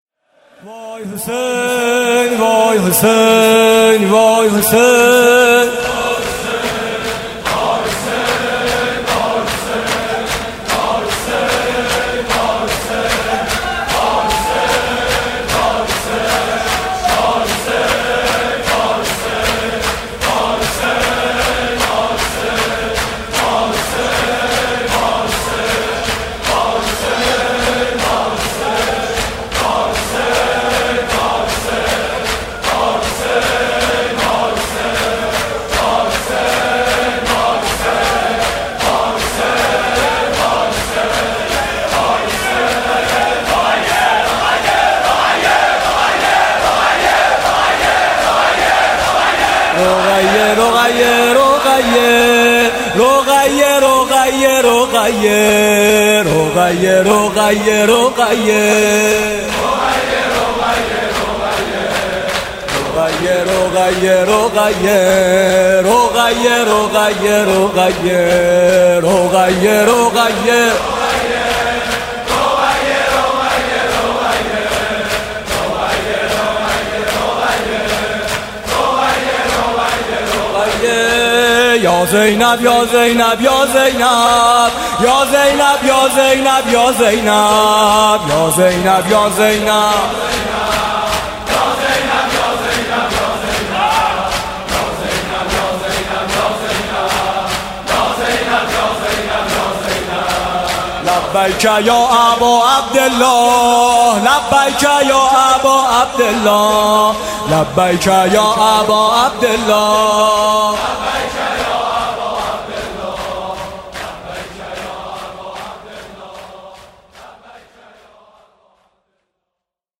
نوا